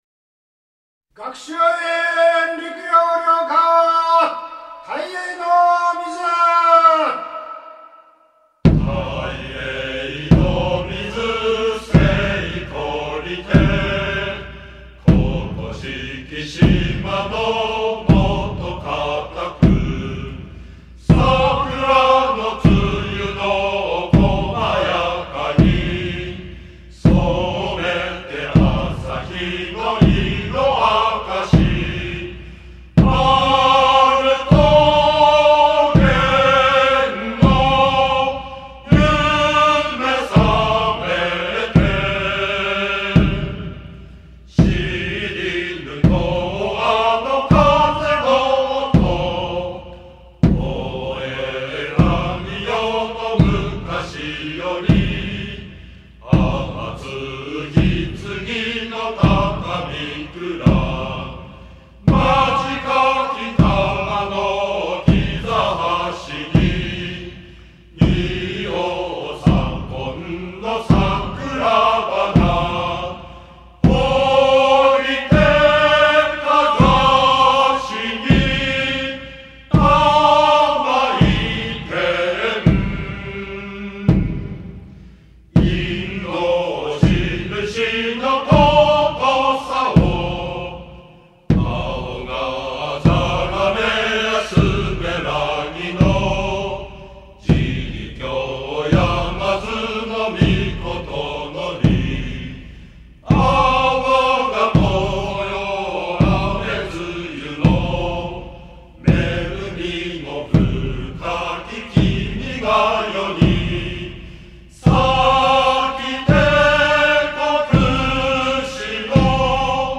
学習院寮歌　大瀛の水